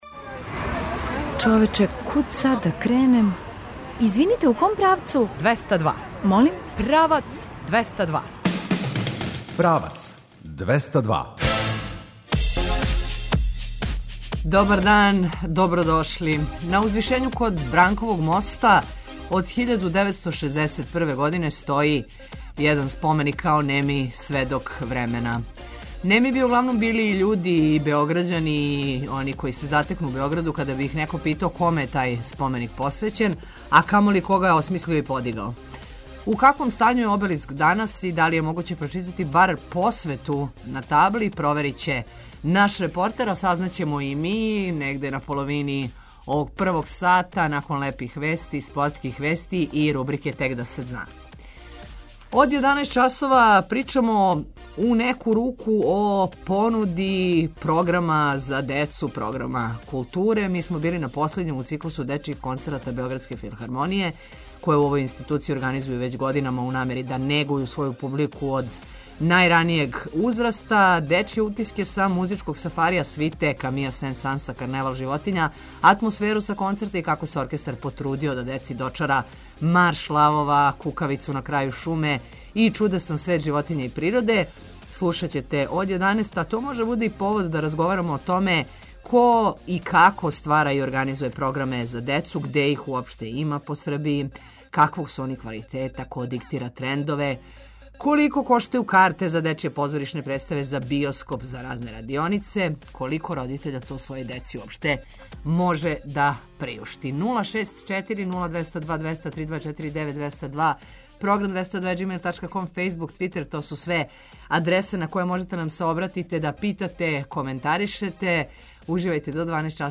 Дечје утиске са „музичког сафарија”, свите Камија Сен-Санса „Карневал животиња”, атмосферу са концерта и како се оркестар потрудио да деци дочара марш лавова, кукавицу на крају шуме и чудесан свет животиња и природе слушаћете од 11 часова.